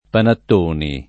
[ panatt 1 ni ]